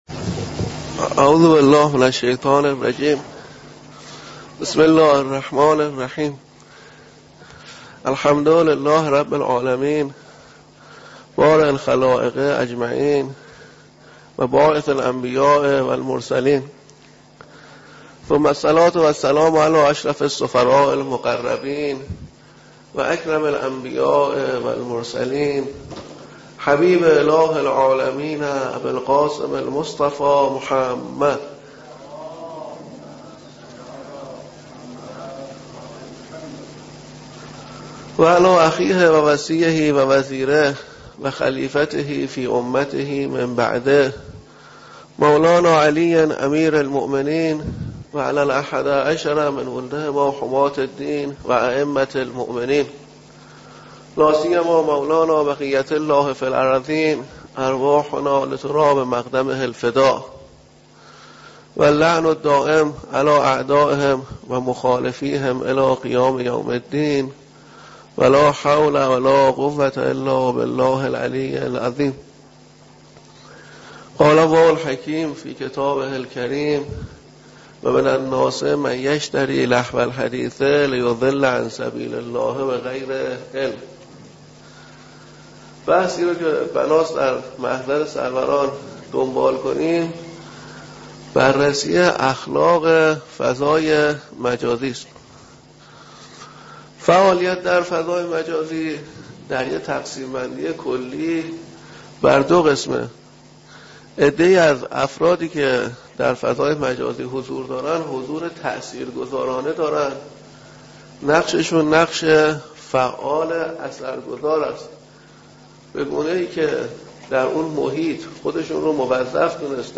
درس اخلاق فضای مجازی